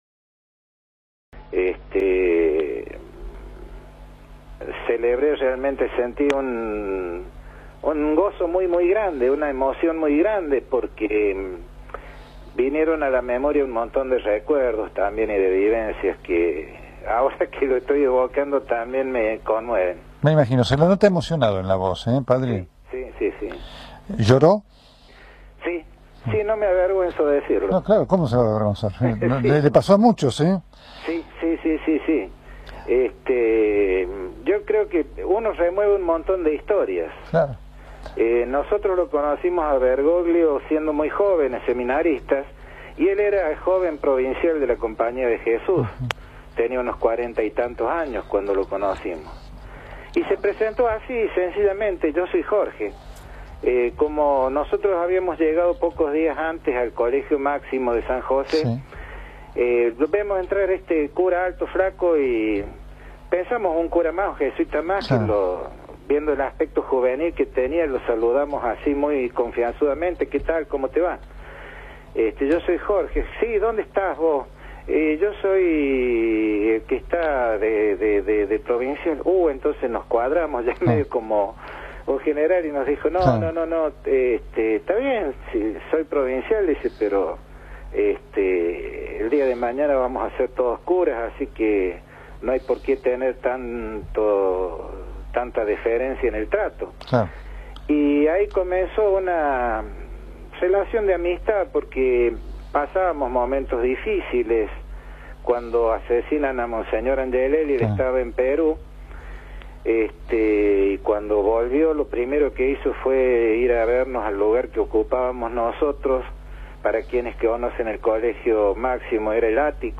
por Radio Continental